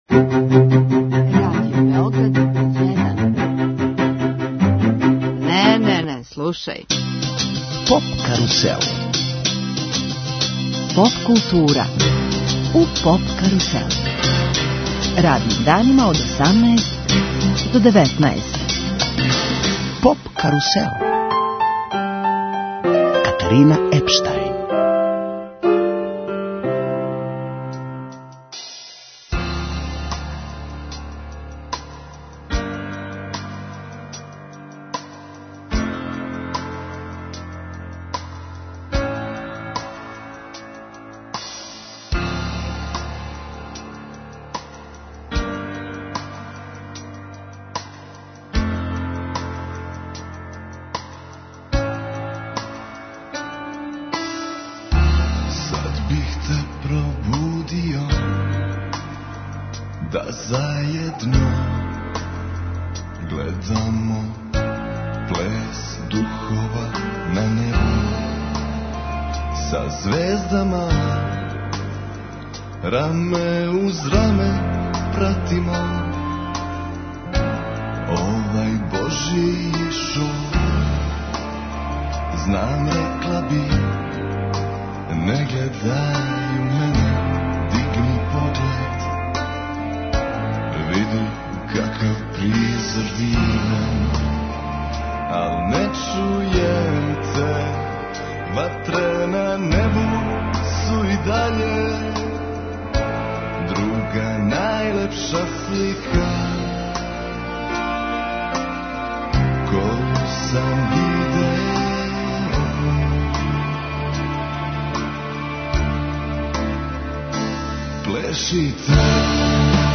У Врбасу ће се одржати Heart Rock festival, први пут. Гости емисије су чланови састава Ничим изазван.